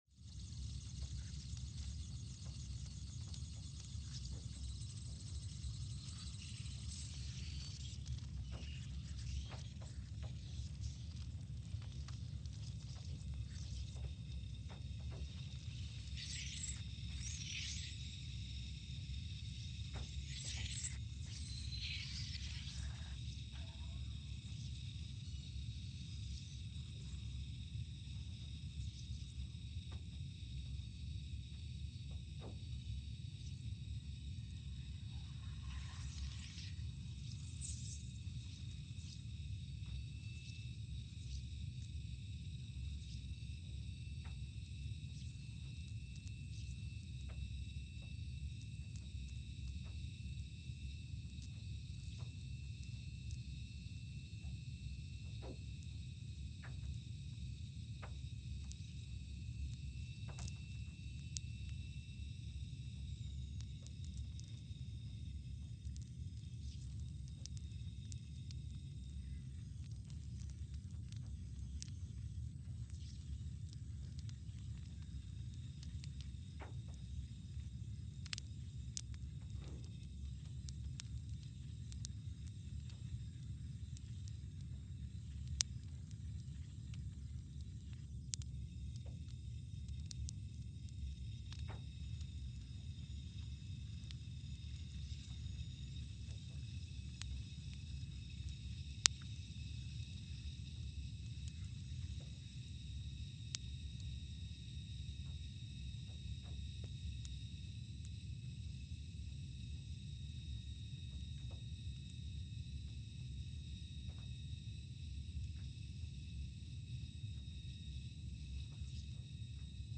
Scott Base, Antarctica (seismic) archived on November 6, 2020
Sensor : CMG3-T
Speedup : ×500 (transposed up about 9 octaves)
Loop duration (audio) : 05:45 (stereo)
Gain correction : 25dB